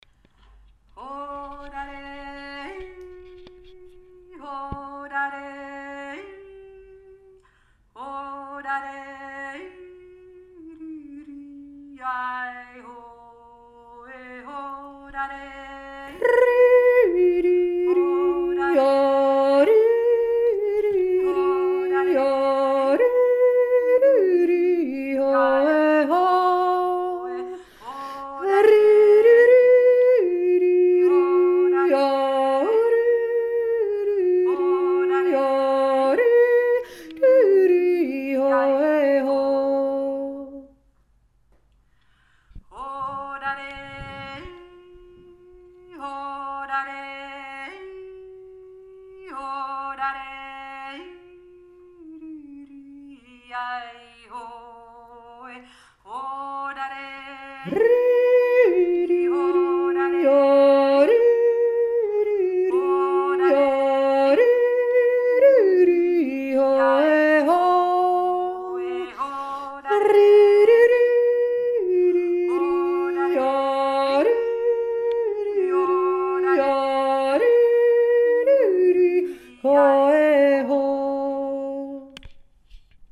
Ein schöner Verschränkter der alle Stückeln spielt, wunderbar um aufeinander zu hören und zu spüren wie die Stimmen sich ineinander verweben. Jede für sich ist eher einfach, doch gemeinsam ergeben sie einen wunderbaren Alpenklangraum...
Hauptstimme und hohe Stimme
riesermuattermittlereundhohe.mp3